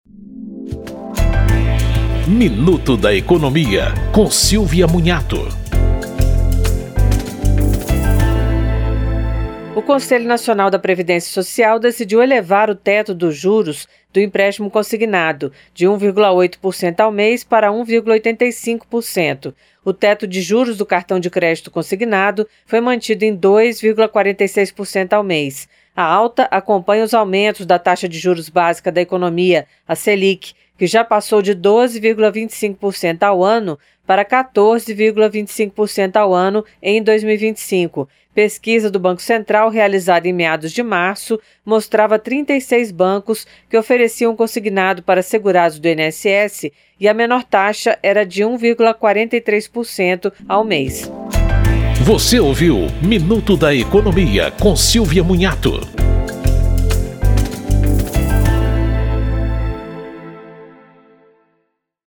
Programas da Rádio Câmara